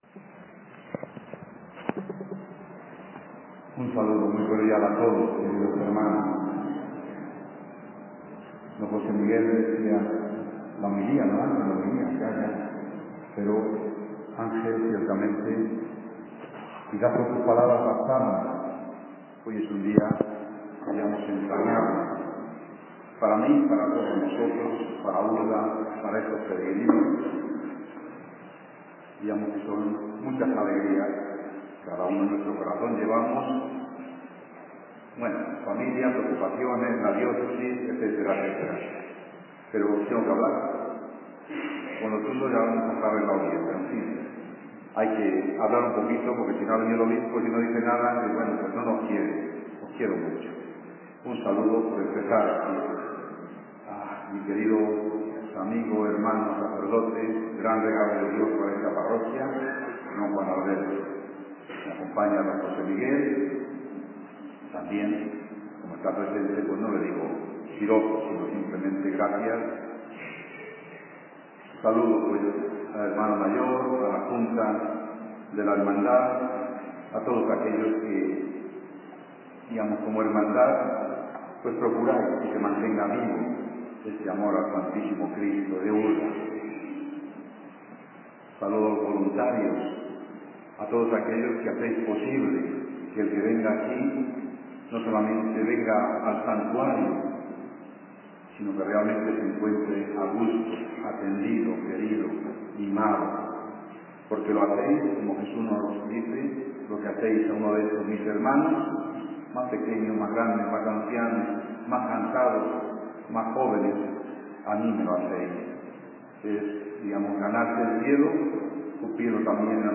El Sr. Obispo Auxiliar, Mons. Ángel Fernández Collado, presidía la solemne Eucaristía dominical en el Santuario del Stmo. Cristo de la Vera Cruz de Urda el pasado domingo día 24 de septiembre.
Homilia-Urda-24-9-17.mp3